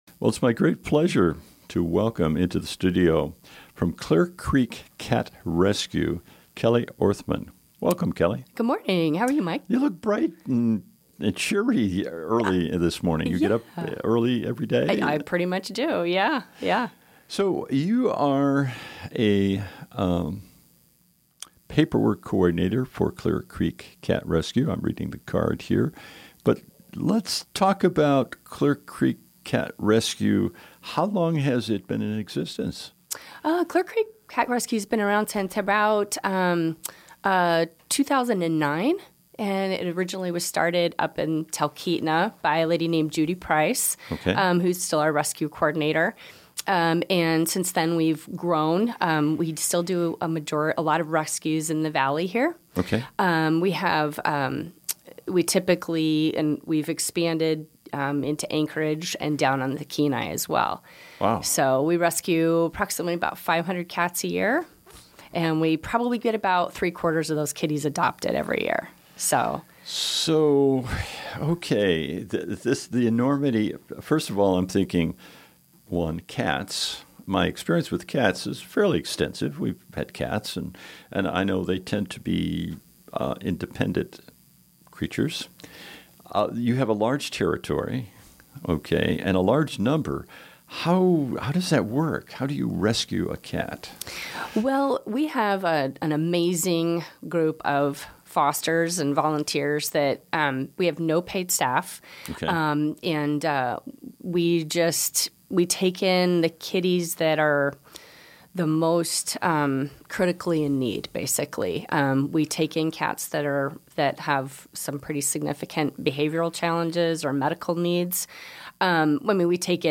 Pick, Click and Give Interview: Clear Creek Cat Rescue 2018-12-21